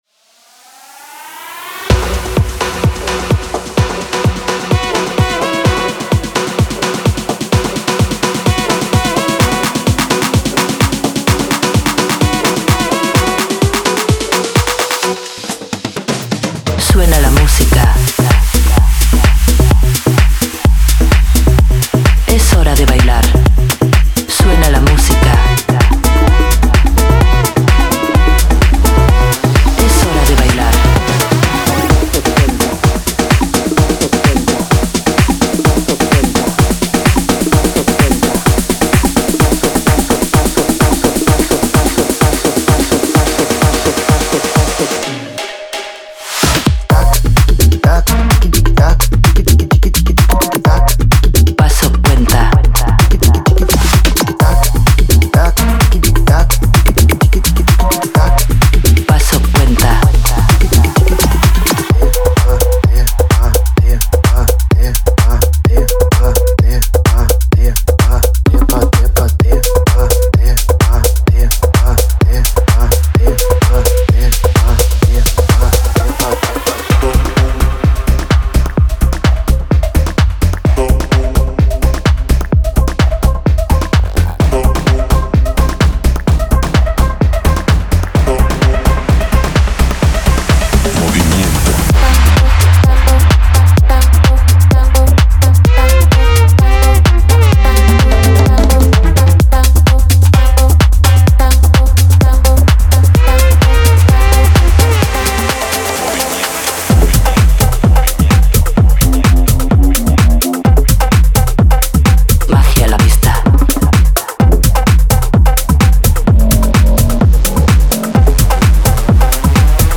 デモサウンドはコチラ↓
Genre:Tech House
128 BPM
41 Bass Loops
41 Vocal Chop Loops
44 Spanish Vocal Phrases (24 Dry, 20 Wet)
31 Percussion Loops